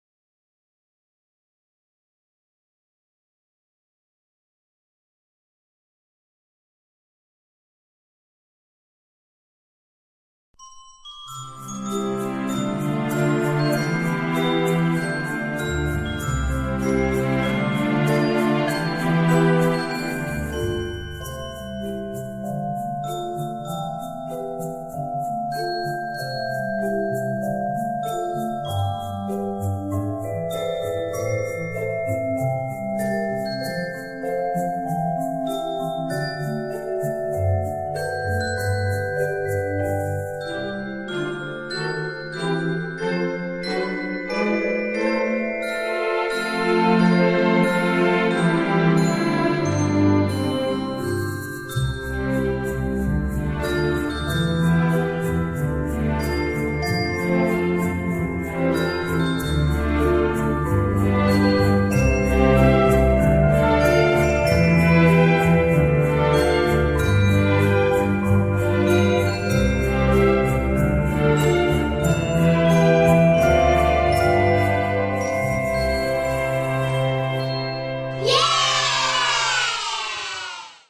相關檔案（本單元樂曲係由著作權人國家表演藝術中心國家交響樂團及國立實驗合唱團所演奏及演唱，經同意授權僅供非營利下載使用。）